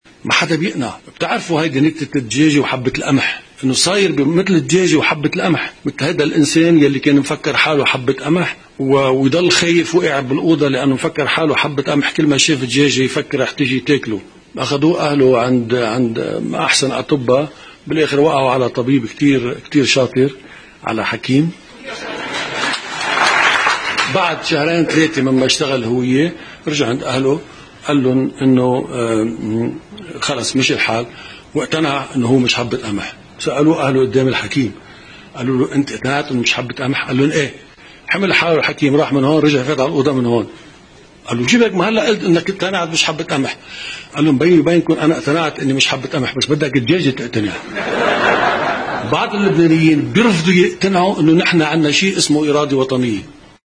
تسجيل صوتي لجعجع عن نكتة حبة القمح والدجاجة